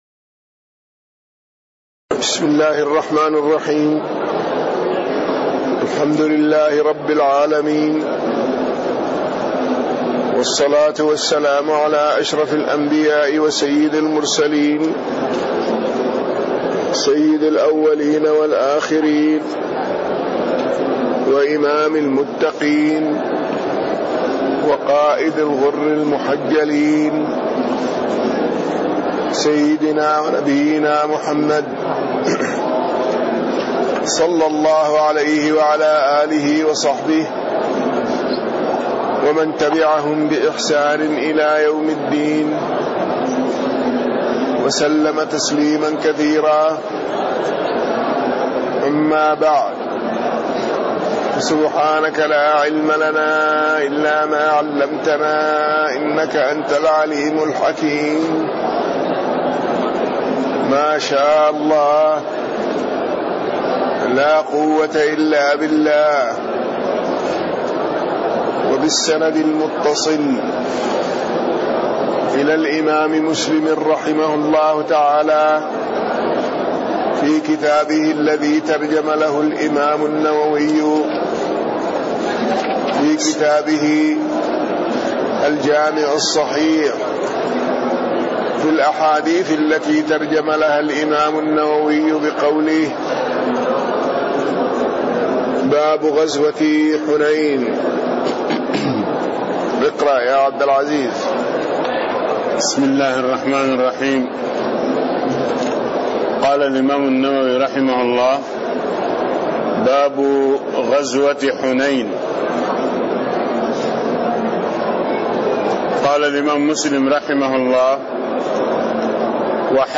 تاريخ النشر ٢٩ شوال ١٤٣٥ هـ المكان: المسجد النبوي الشيخ